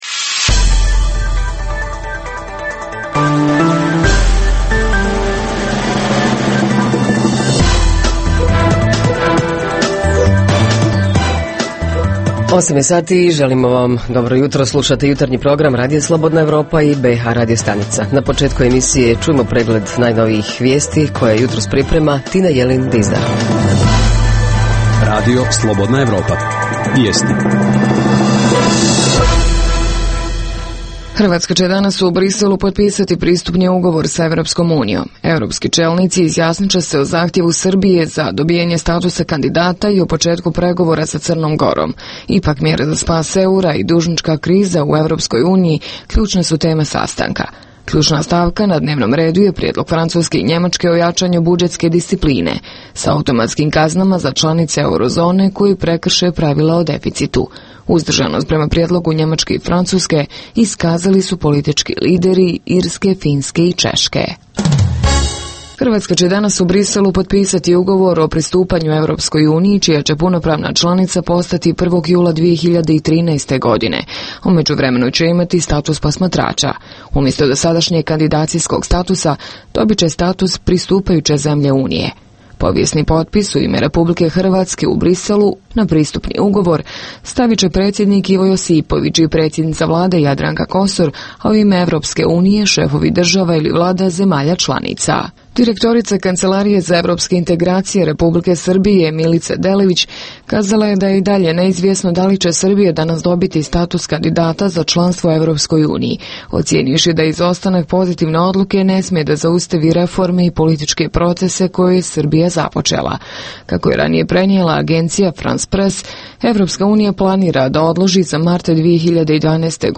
Jutarnji program za BiH koji se emituje uživo. Tema ovog jutra vezana je za 10. decembar (subota) kada se obilježava Međunarodni dan ljudskih prava.
Redovni sadržaji jutarnjeg programa za BiH su i vijesti i muzika.